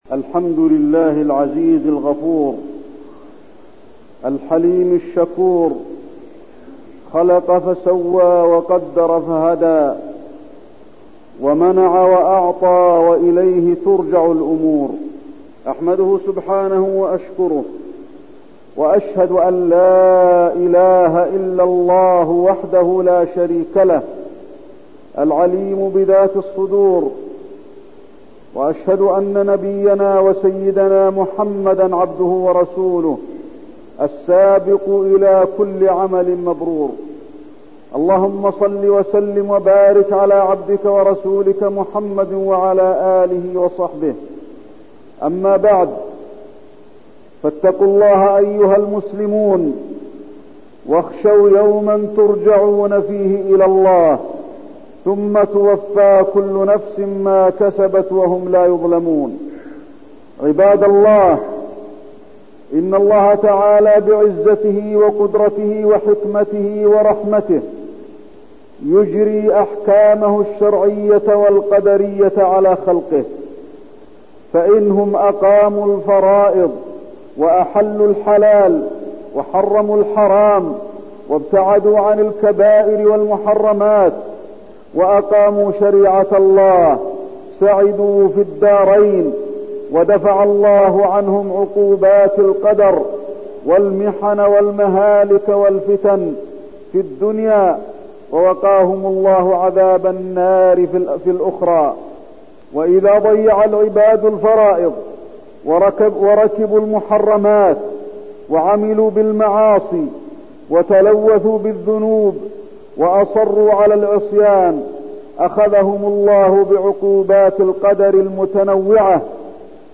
تاريخ النشر ١ ربيع الثاني ١٤١١ هـ المكان: المسجد النبوي الشيخ: فضيلة الشيخ د. علي بن عبدالرحمن الحذيفي فضيلة الشيخ د. علي بن عبدالرحمن الحذيفي حاجة الأمة للرجوع للدين